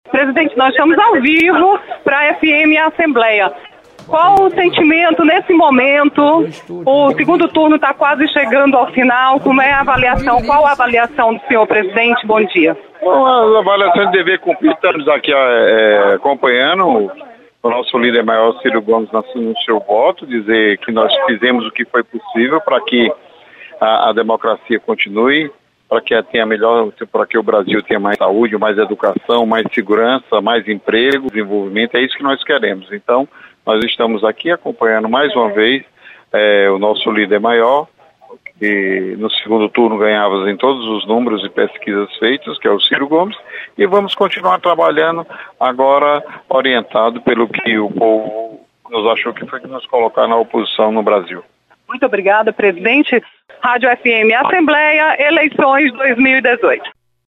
Presidente da Assembleia, Deputado Zezinho Albuquerque, acompanha votação na capital cearense. Repórter